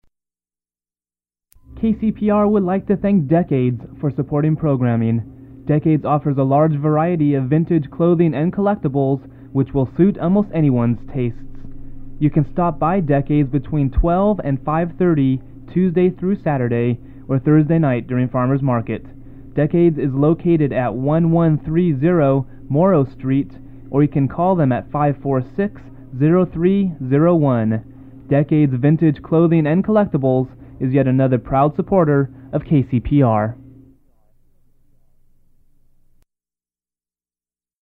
Decades Vintage Clothing [commercial]
Form of original Audiocassette